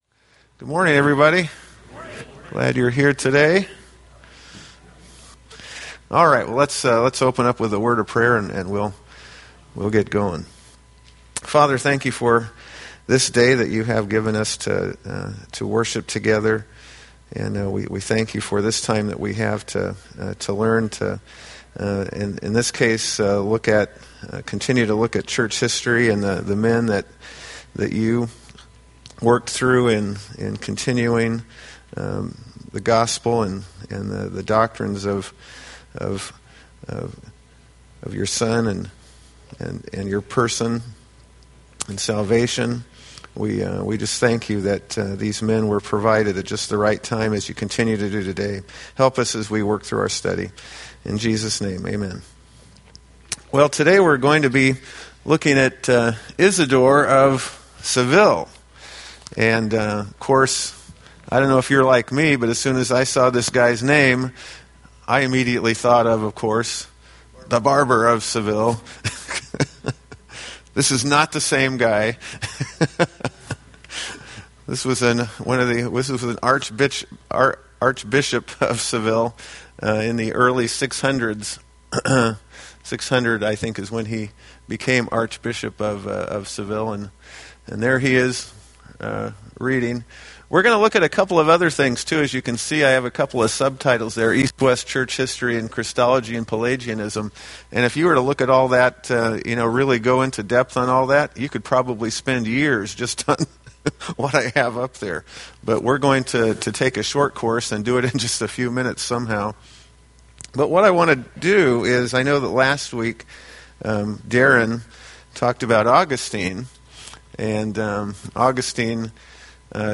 Date: Apr 26, 2015 Series: Historical Men of Faith Grouping: Sunday School (Adult) More: Download MP3